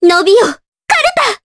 Ophelia-Vox_Skill1_jp.wav